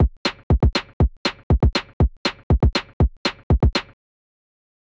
The audio examples below compare a drum beat uncompressed with the orignal samples against a compressed one passed through a 2A03 emulator.
Beat_uncomp.wav